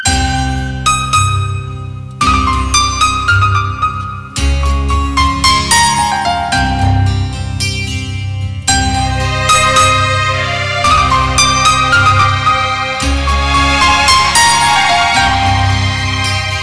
• Bollywood Ringtones